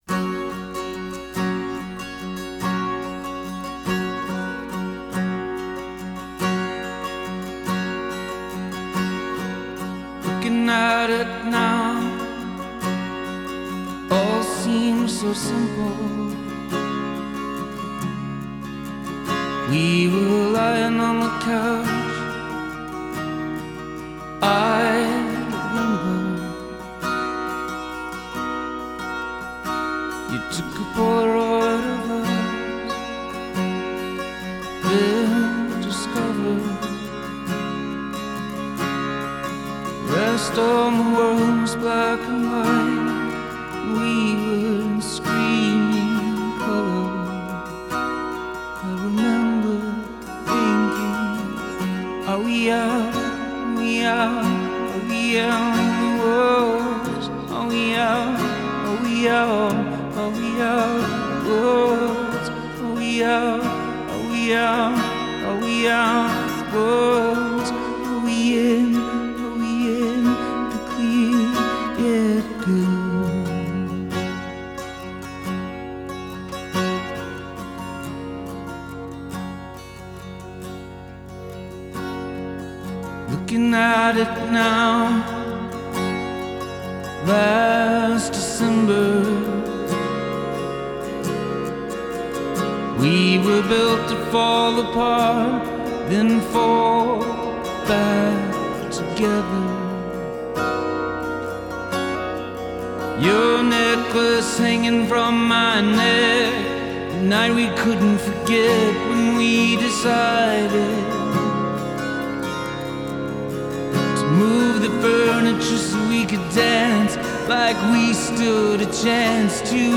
Genre: Rock, Folk, Pop, Covers